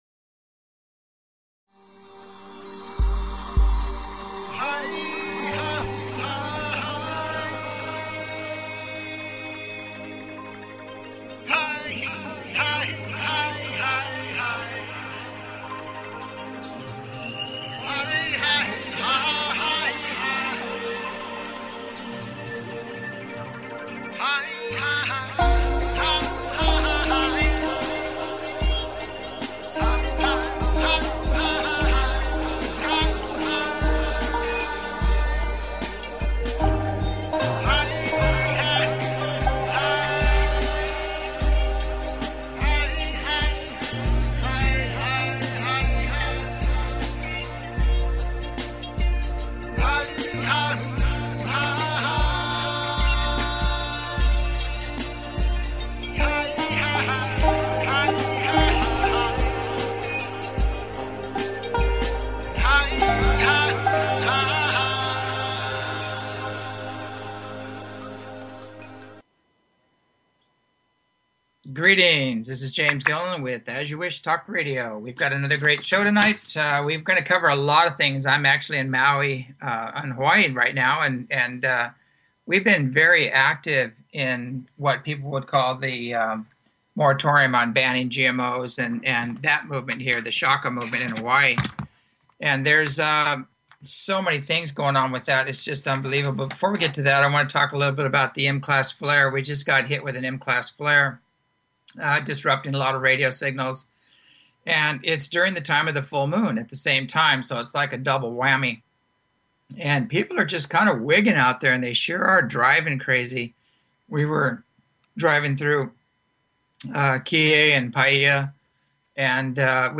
As You Wish Talk Radio